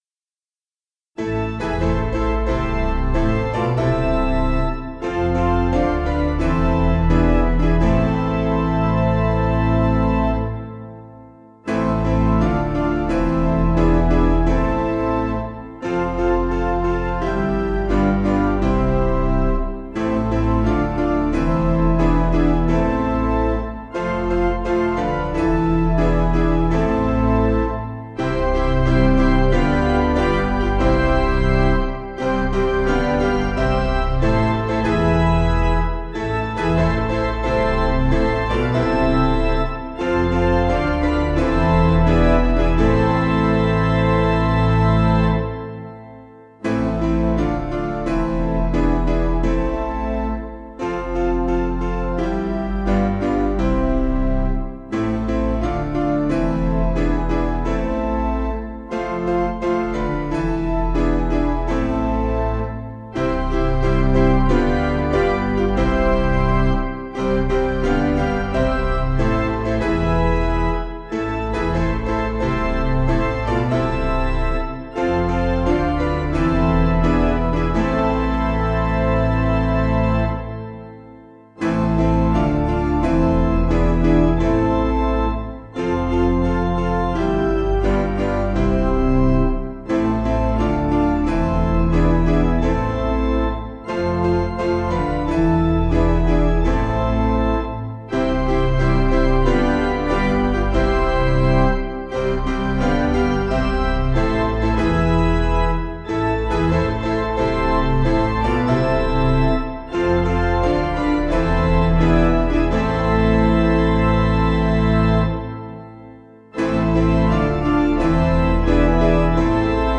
Basic Piano & Organ
(CM)   5/Am